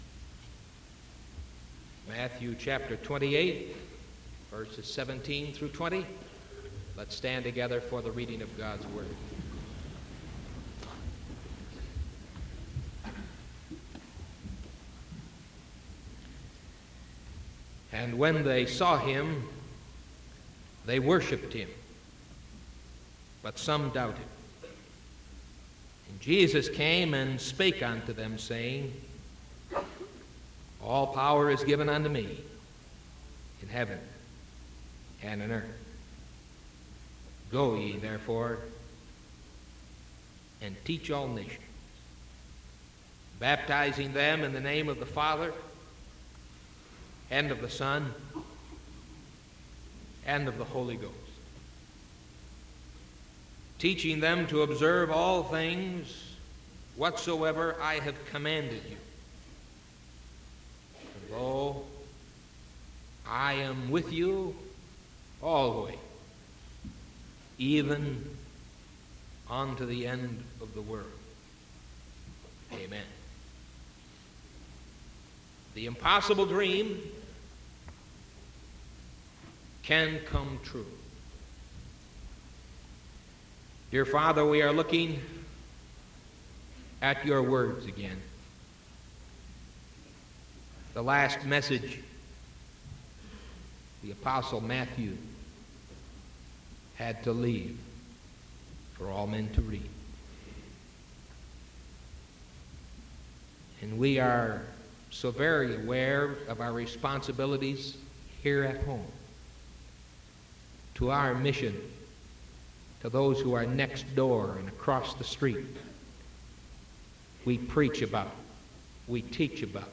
Sermon from November 17th 1974 AM